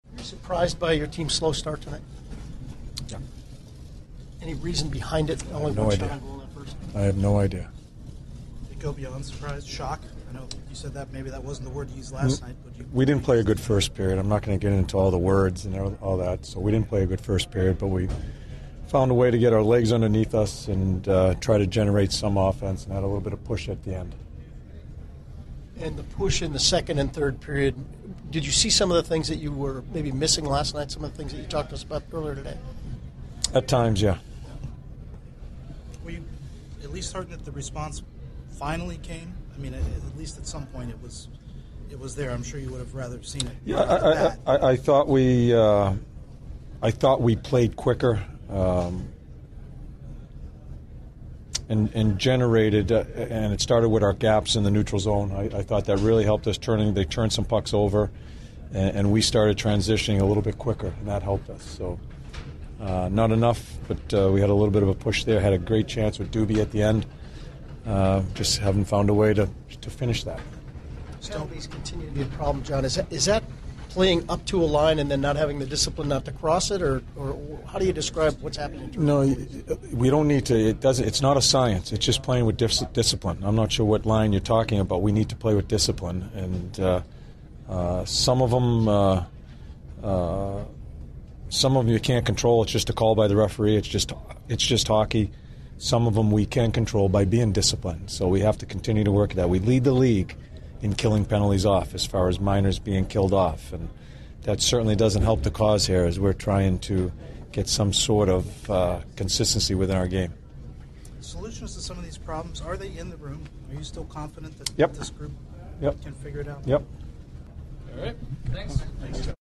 CBJ Interviews / John Tortorella Post-Game 12/27/15